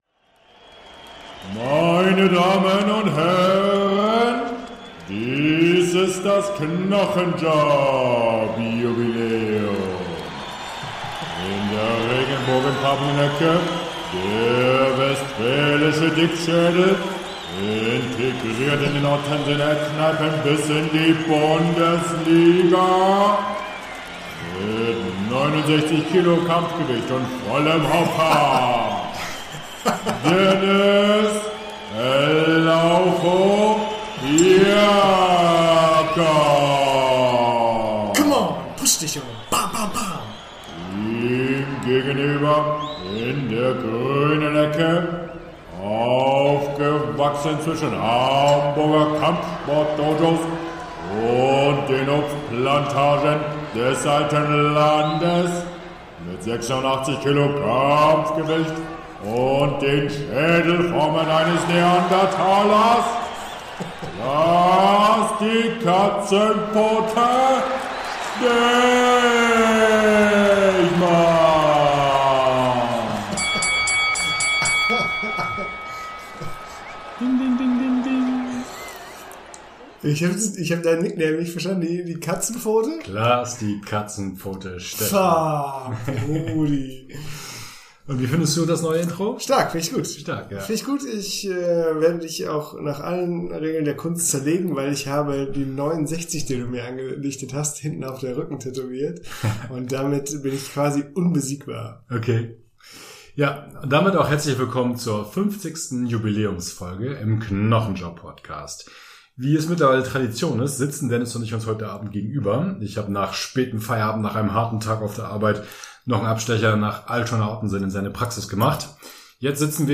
Eine launige, feucht fröhliche Folge ist unten raus gekommen, das kann man sagen. Voodoo, Schwurbel, Gesundheitsamt, Übergewicht und andere Reizthemen, die Knochenjob-Boys nehmen Maß.